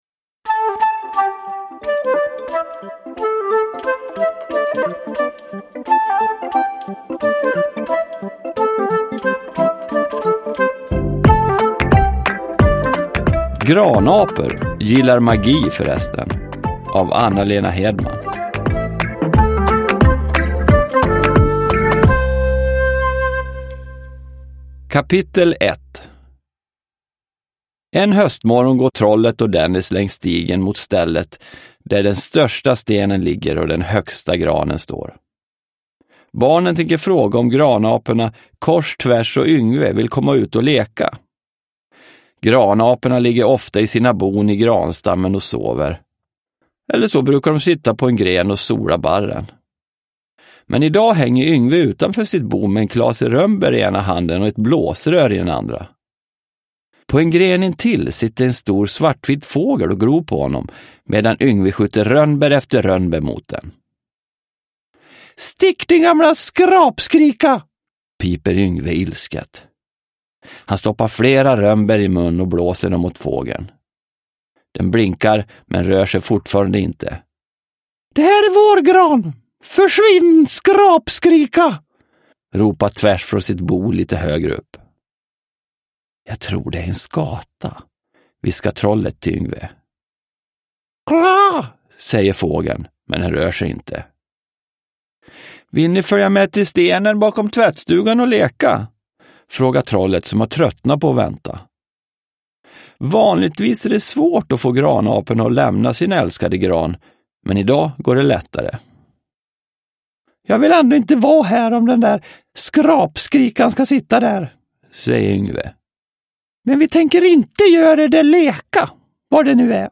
Granapor gillar magi, förresten – Ljudbok – Laddas ner